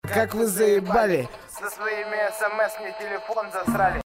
Рэп, Хип-Хоп, R'n'B